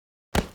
马撞击到物体.wav